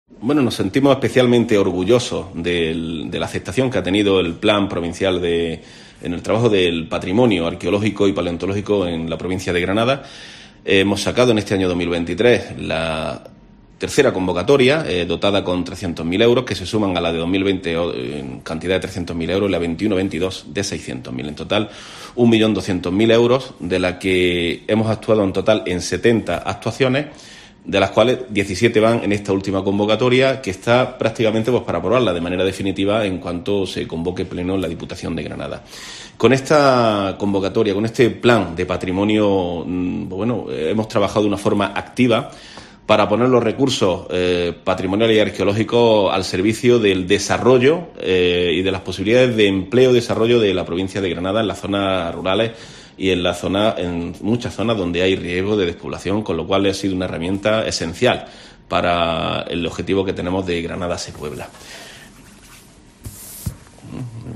Diputado de Obras Públicas y Vivienda, José María Villegas